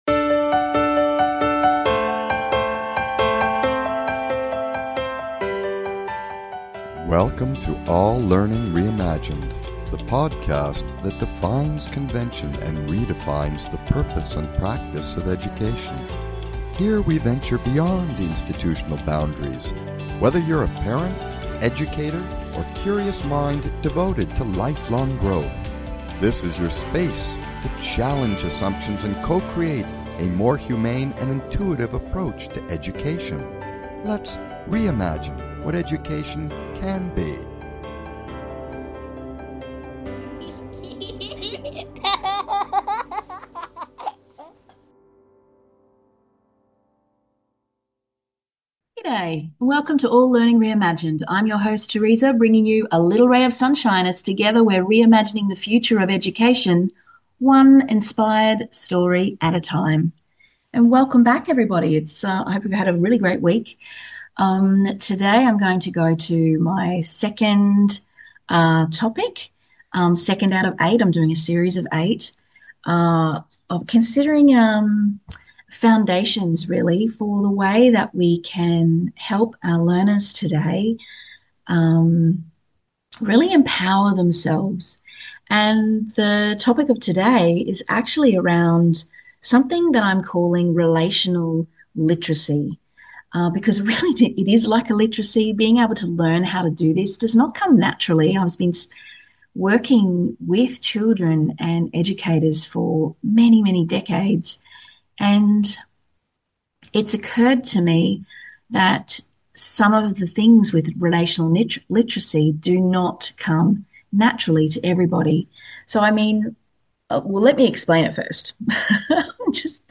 Talk Show Episode, Audio Podcast, All Learning Reimagined and Relational Literacy on , show guests , about Relational Literacy,Reimagining education through the lens of human connection and emotional safety,Kids don't learn from people they don't like,Relationship is not the bonus it is the foundation,Core Pillars of Literacy,Self-Awareness,Safety First,The nervous system determines if learning is accessible,Fear reduces cognition, categorized as Health & Lifestyle,Love & Relationships,Physics & Metaphysics,Psychology,Mental Health,Religion,Self Help,Society and Culture,Spiritual